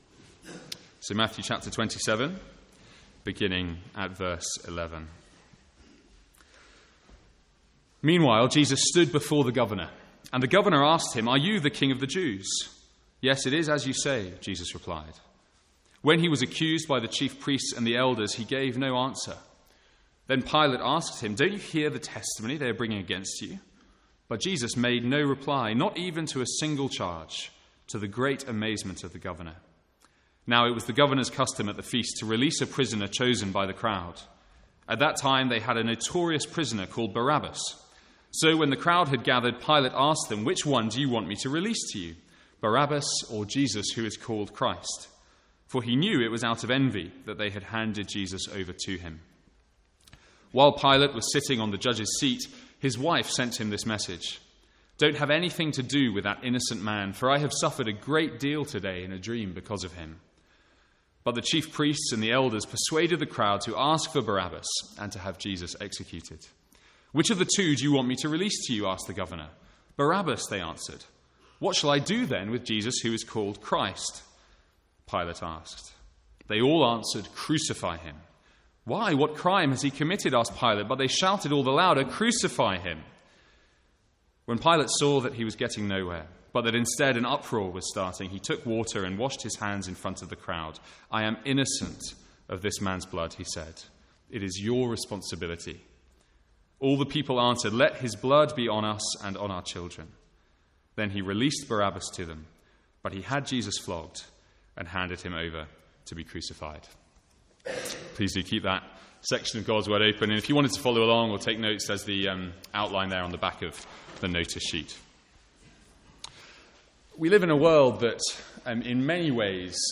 Sermons | St Andrews Free Church
From the Sunday morning series in Matthew.